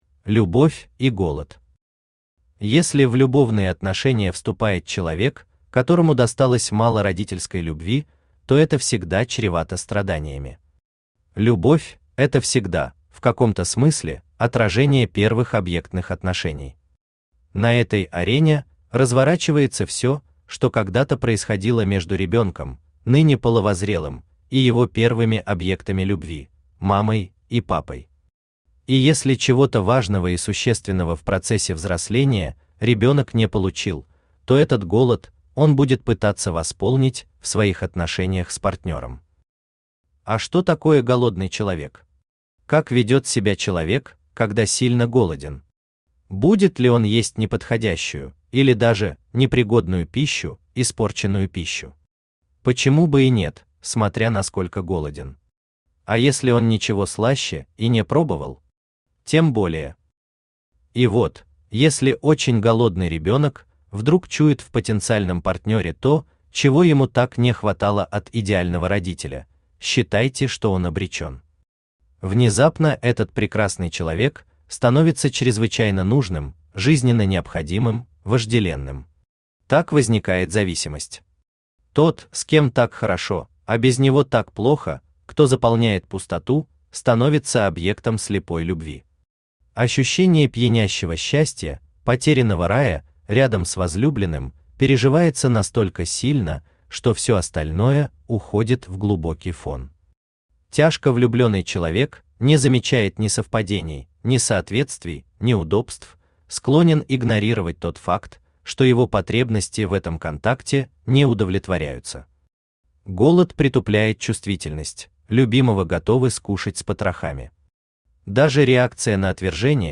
Аудиокнига Приемный покой. Советы на каждый день Том 7 | Библиотека аудиокниг
Советы на каждый день Том 7 Автор Геннадий Анатольевич Бурлаков Читает аудиокнигу Авточтец ЛитРес.